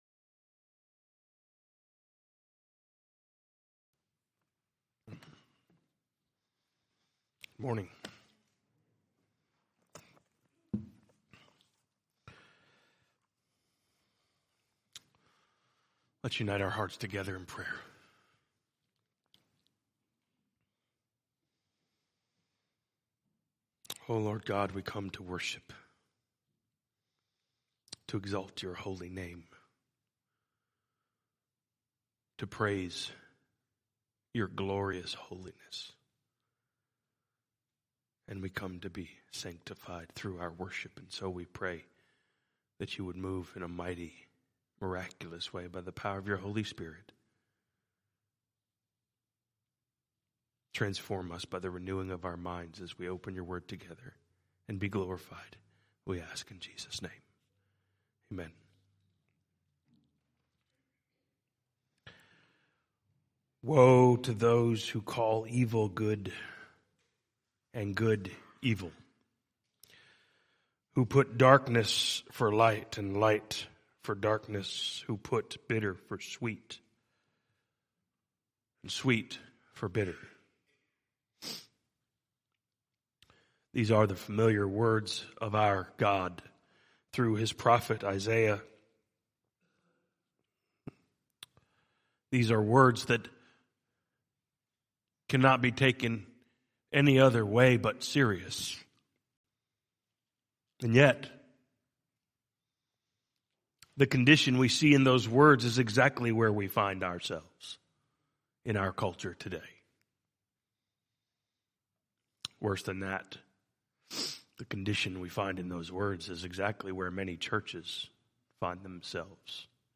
A message from the series "2022 Sermons."